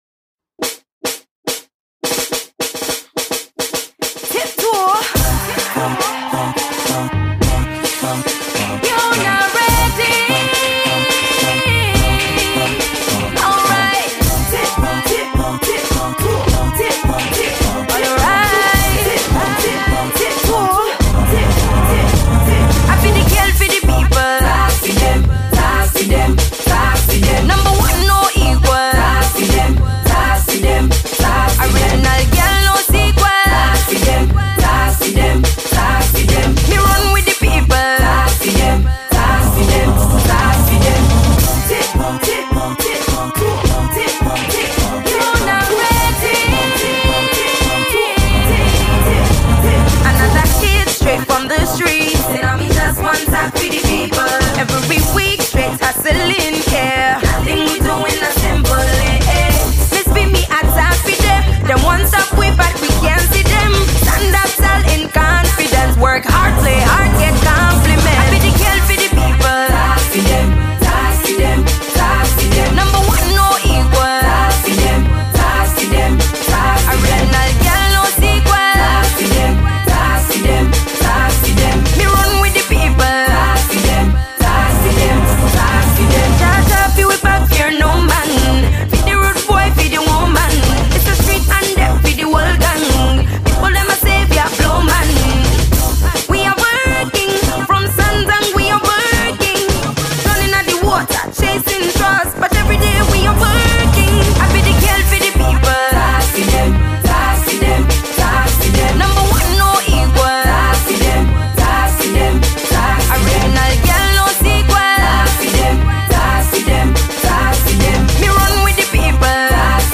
Ghanaian Dancehall Queen
Good Anthemic Track